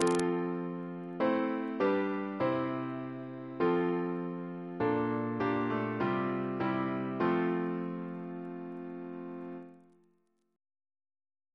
Single chant in F Composer: William Dyce (1806-1864) Reference psalters: ACB: 285; OCB: 142; PP/SNCB: 35